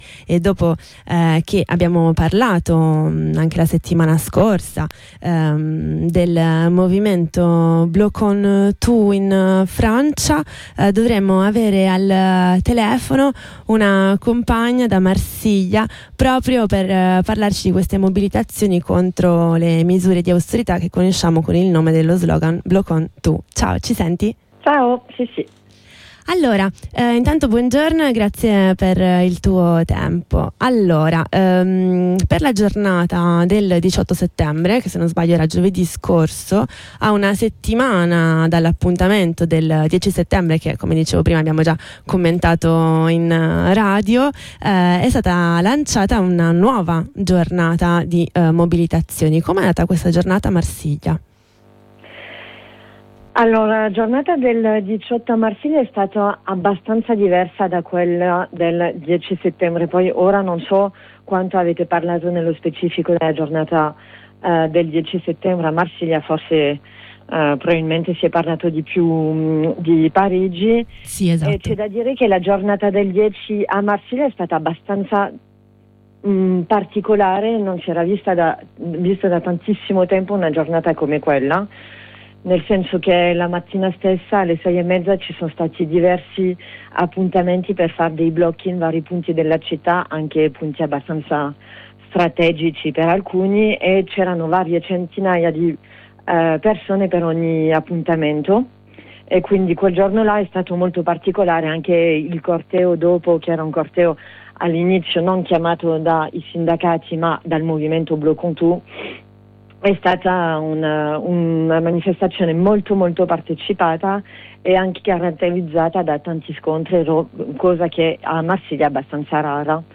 Ne parliamo con una compagna di Marsiglia